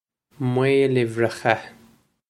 Pronunciation for how to say
mwale-ivrikha
This is an approximate phonetic pronunciation of the phrase.